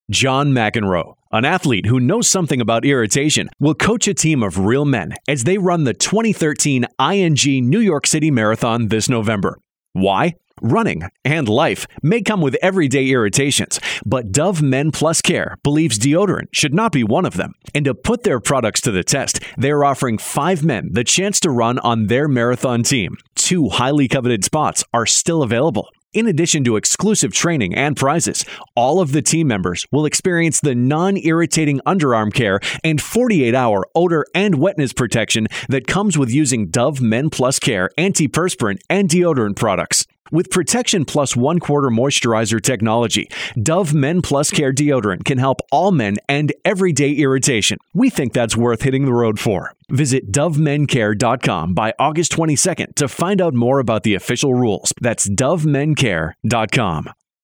August 14, 2013Posted in: Audio News Release